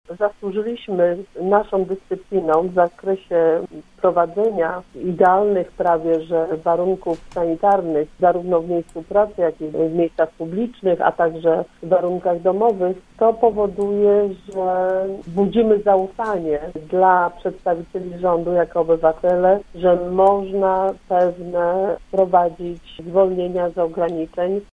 Radna Prawa i Sprawiedliwości była gościem Rozmowy Punkt 9.